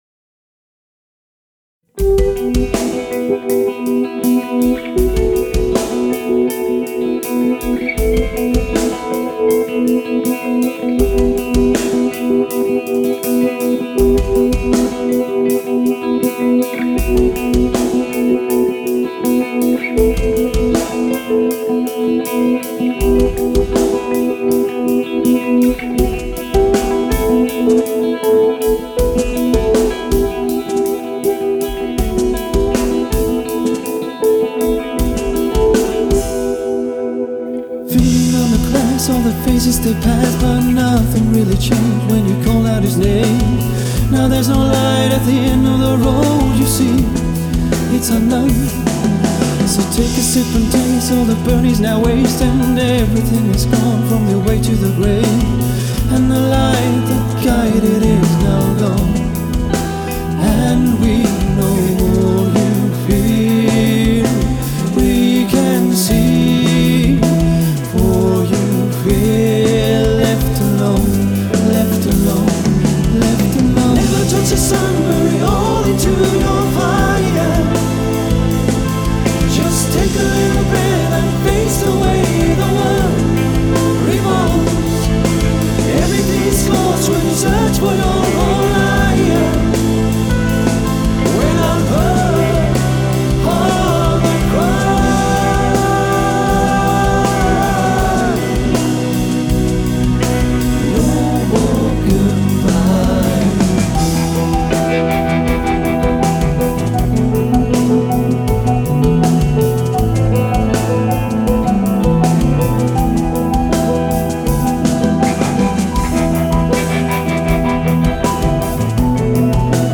آهنگ راک rock music Rock موزیک‌ راک